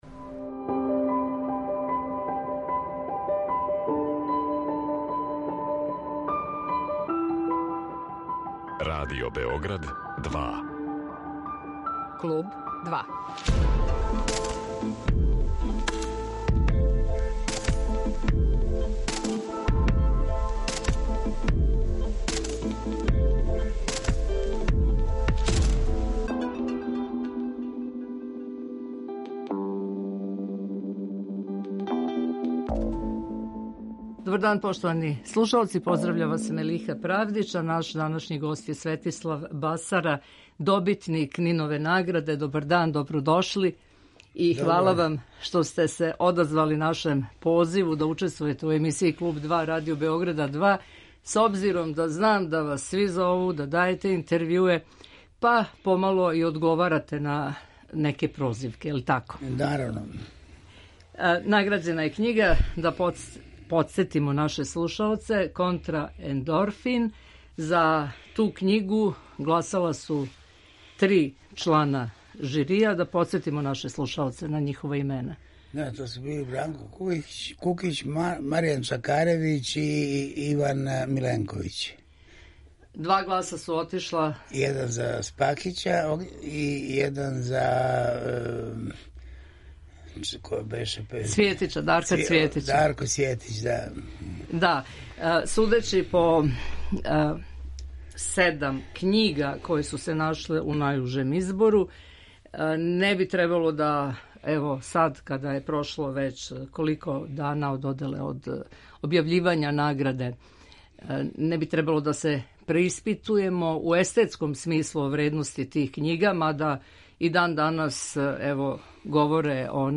Гост Клуба 2 је Светислав Басара, добитник Нинове награде за књигу „Контраендорфин'